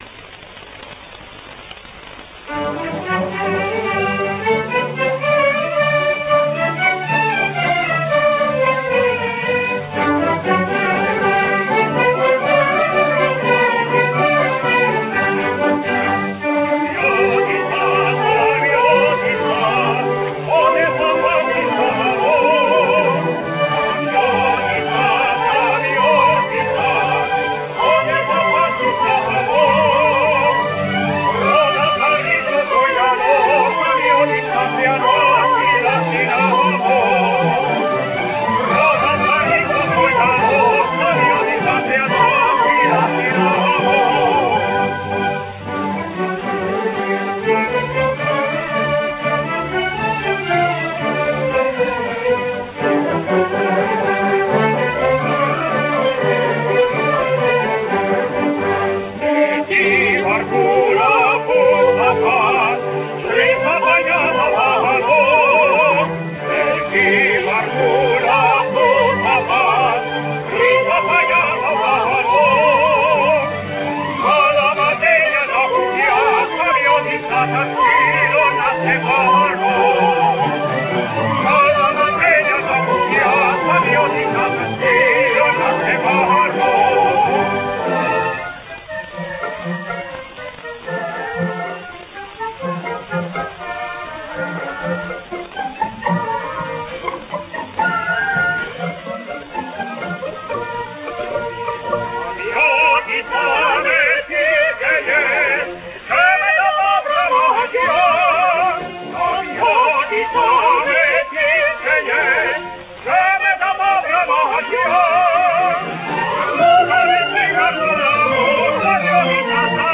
Vidéo de l'orchestre de jeunes de Leipzig
avec 100 musiciens dans le jardin de Hellenikon Idyllion - juillet 1993
Répétition d'une chanson grecque "Samiotissa"
soprano